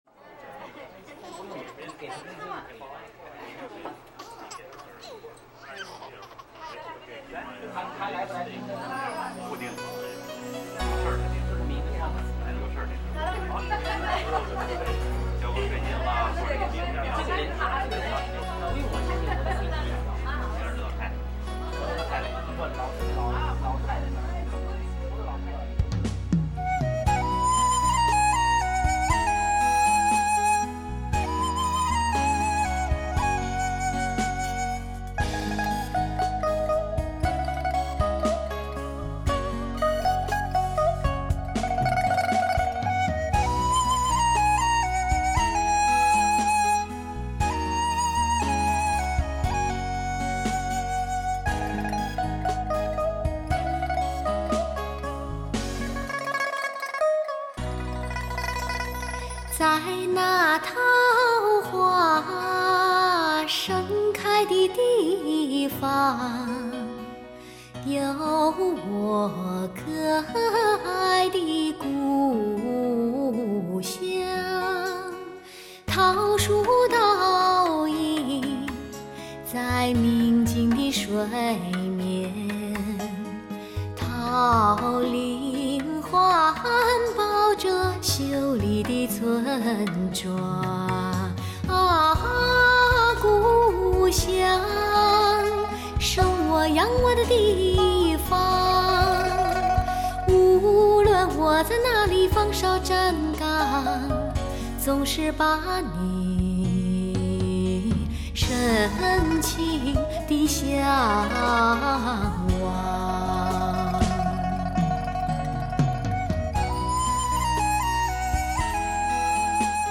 华人音乐世界的360度极致全渗透音效